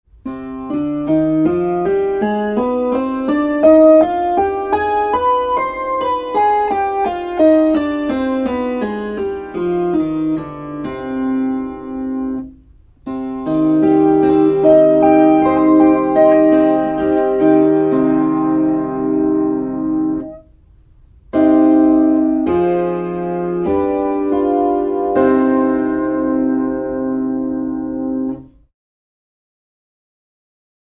c-molli Tästä pääset opetusetusivulle. Marsilaiset marssivat e- ja a-vivut kumoon mutta h-vipu vedetäänkin takaisin.